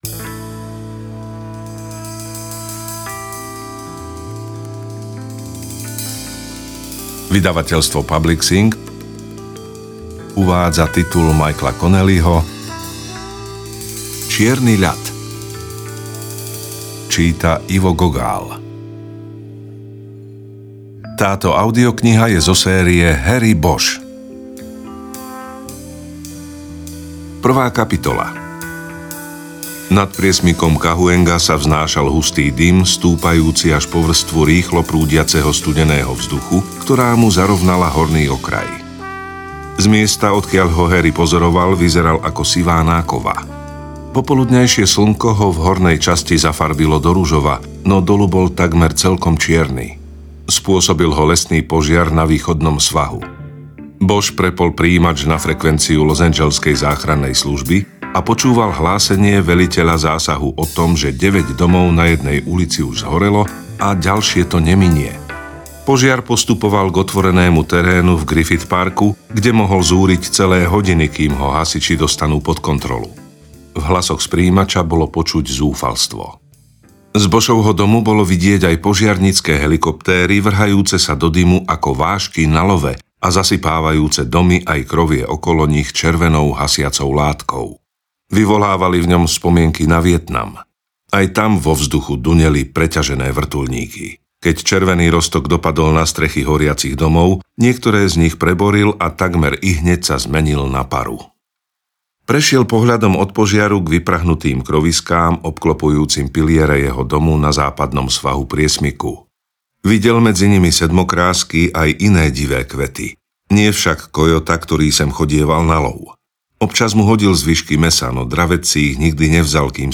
Čierny ľad audiokniha
Ukázka z knihy